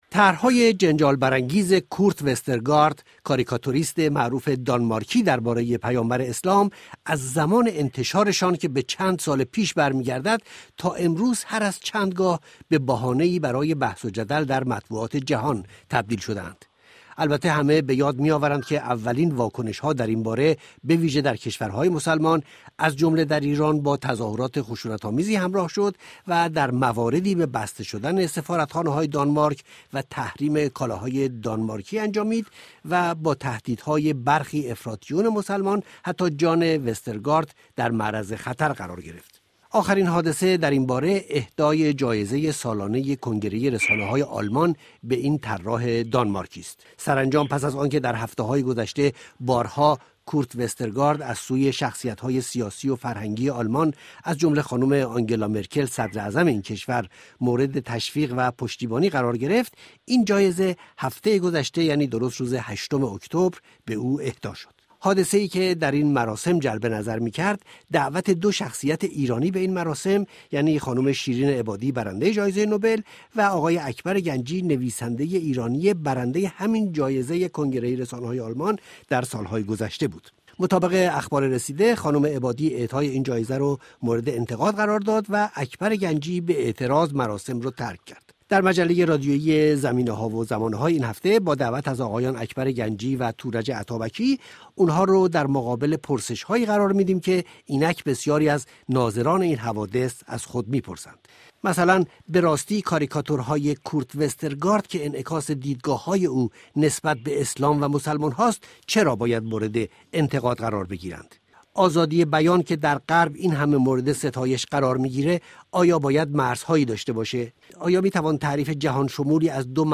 میز گرد این هفته ما، بمناسبت این حادثه، به بحثی در باره مناسبات میان رواداری و حق نقد و تمسخر اختصاص یافته است.